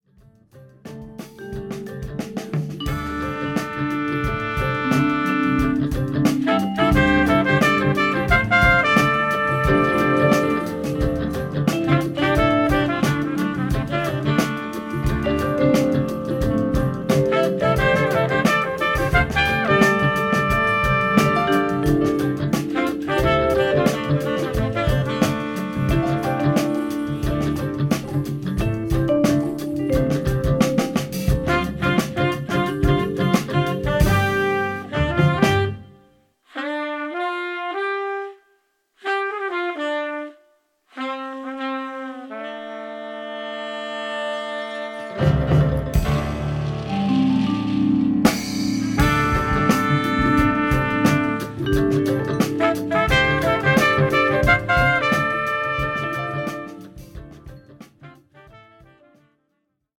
JAZZ / JAZZ FUNK / FUSION
エチオピアの音楽にジャズやラテン音楽などを融合させた「エチオ・ジャズ」